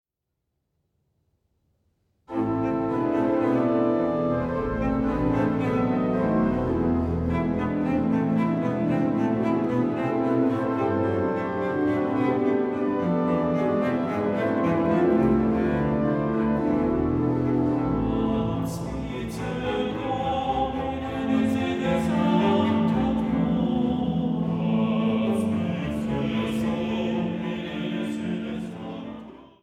Vespergesang